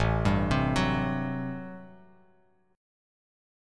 Listen to Am13 strummed